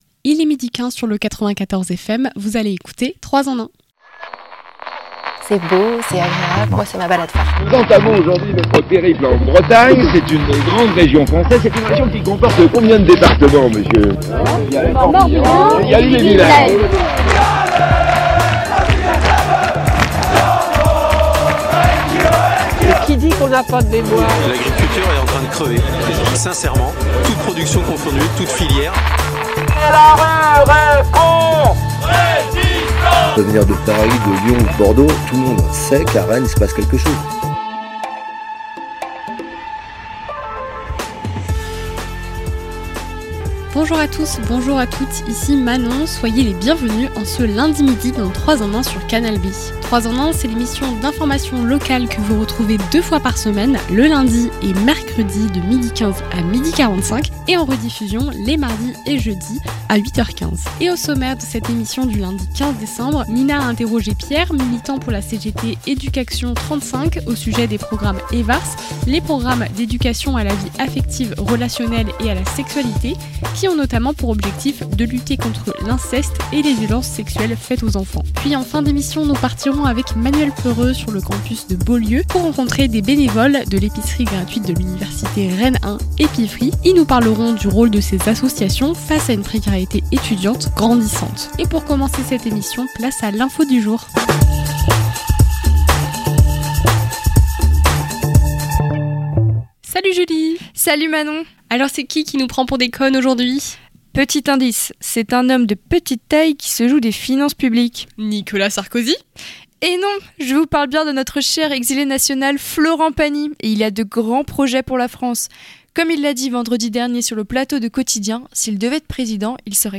Le Reportage
Canal B s'est rendue sur le campus Beaulieu, pour rencontrer des bénévoles de l’épicerie gratuite de l' Université Rennes 1 : Epifree . Ils nous parleront du rôle de ces associations face à la précarité étudiante grandissante à Rennes et partout en France.